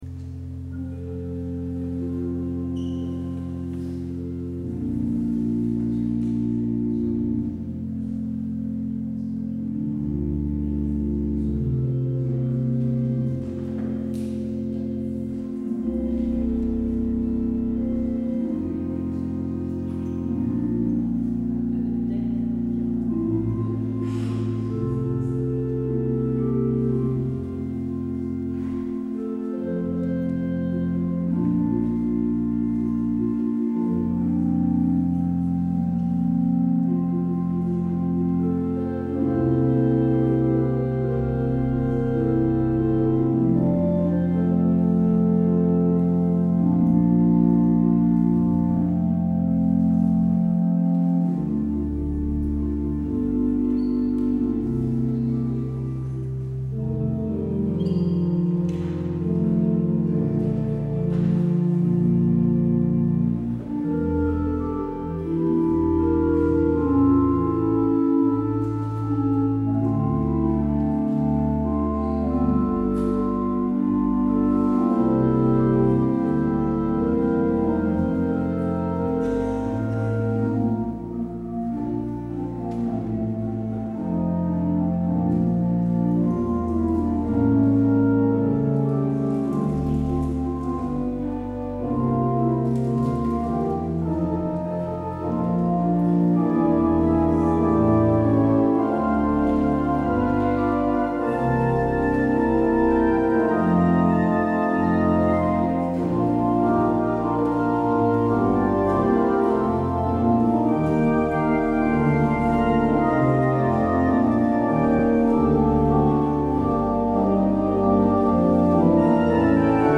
 Luister deze kerkdienst hier terug: Alle-Dag-Kerk 17 oktober 2023 Alle-Dag-Kerk https
Het openingslied is NLB 837: 1 en 4.